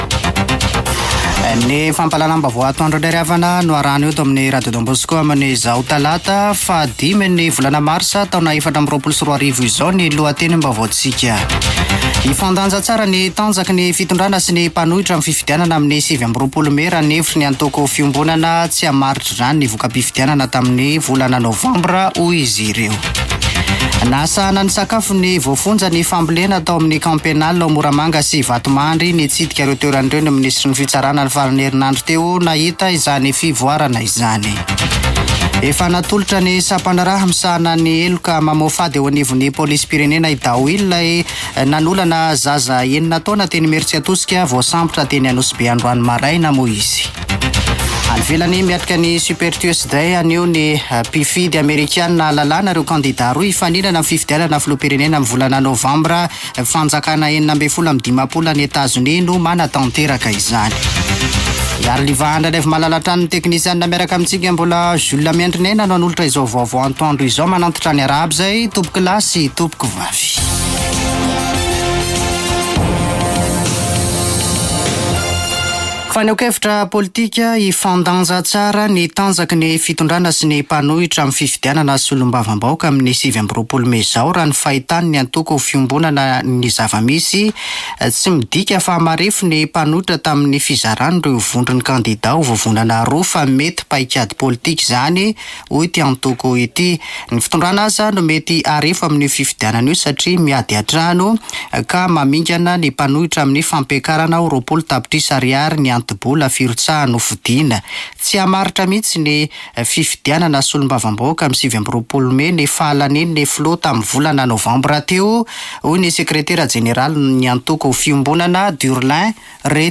Radio Don Bosco - [Vaovao antoandro] Talata 5 marsa 2024